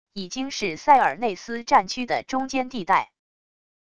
已经是塞尔内斯战区的中间地带wav音频生成系统WAV Audio Player